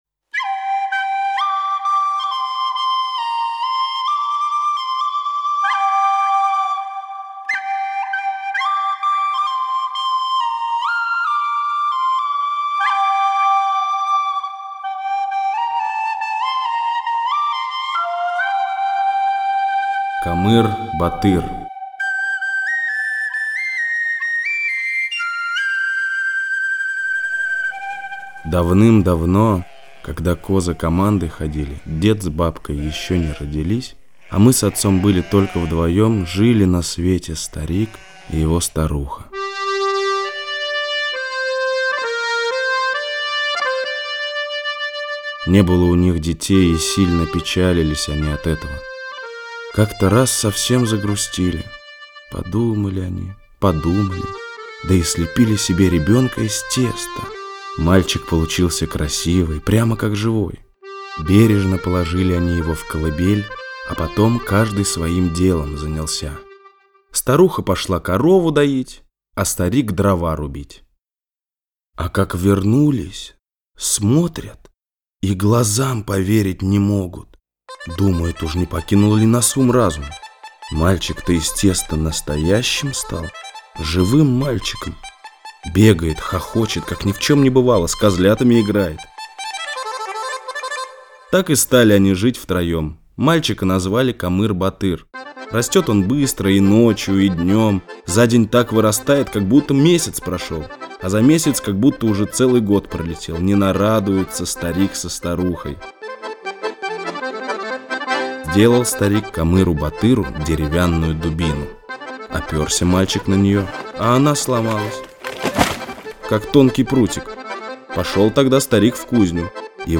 Камыр-батыр - татарская аудиосказка - слушать онлайн